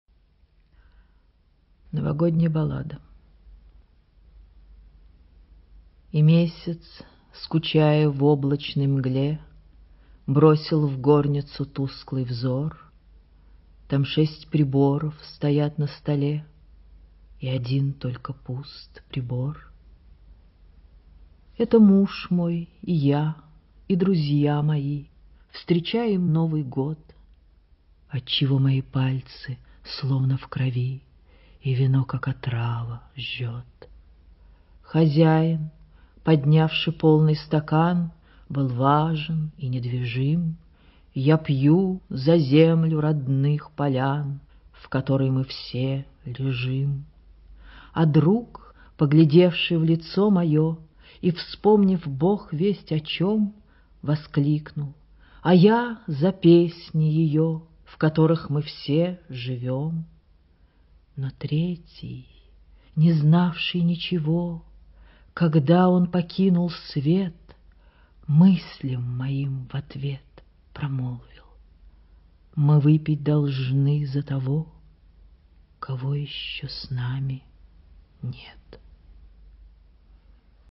anna-ahmatova-chitaet-a-demidova-novogodnyaya-ballada